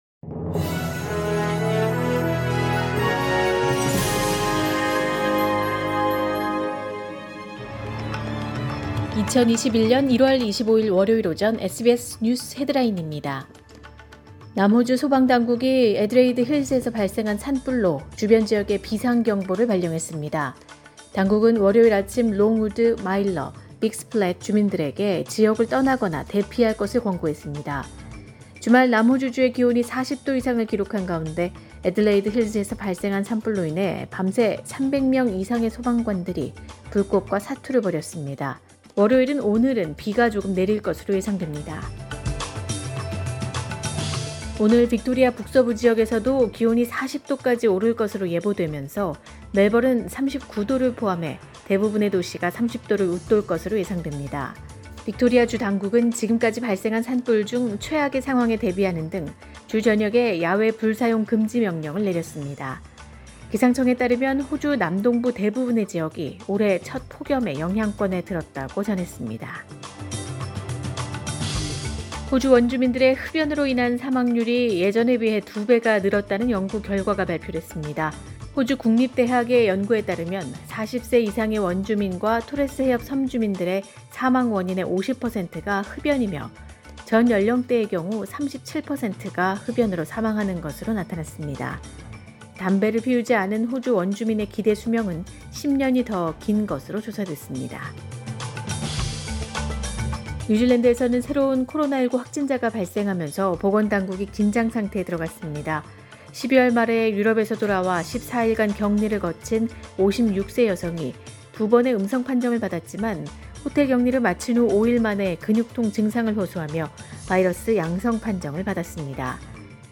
2021년 1월 25일 월요일 오전의 SBS 뉴스 헤드라인입니다.